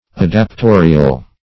Adaptorial \Ad`ap*to"ri*al\
adaptorial.mp3